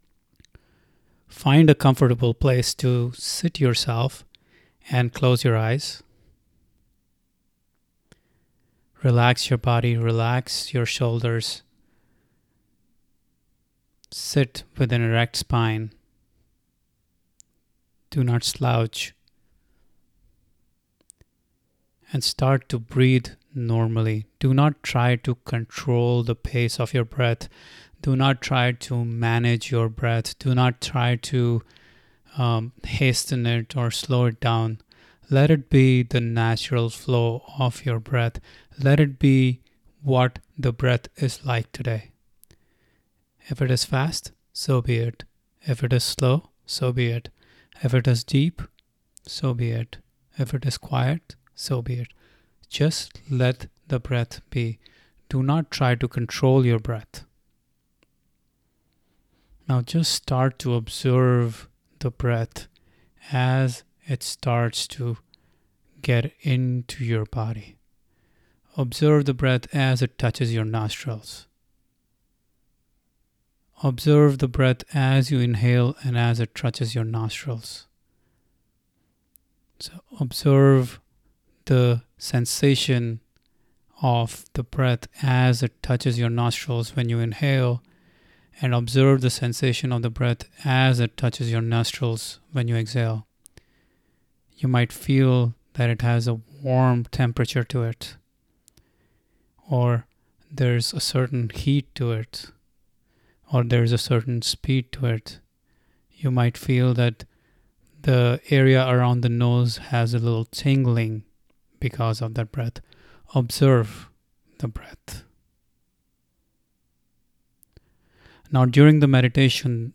Guided Meditation Audio
Breath_15min_guided.mp3